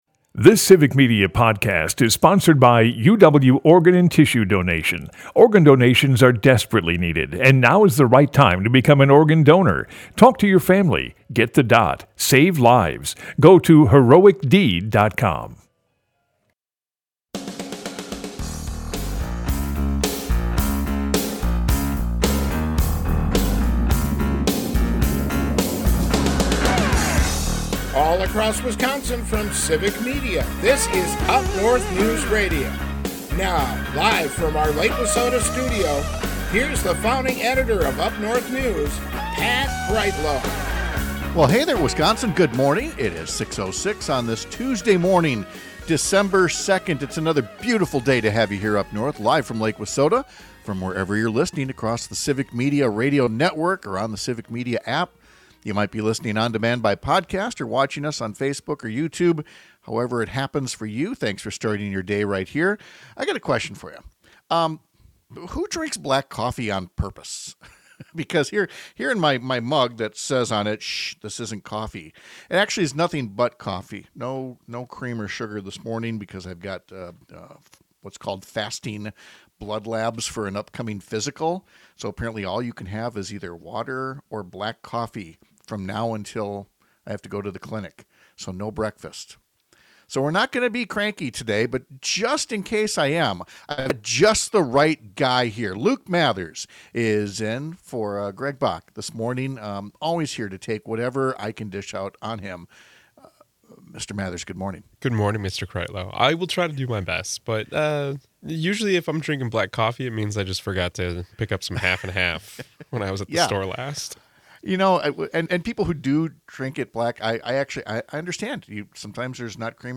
Pat Kreitlow is a longtime Wisconsin journalist and former state legislator who lives in and produces his show from along Lake Wissota in Chippewa Falls.
Broadcasts live 6 - 8 a.m. across the state!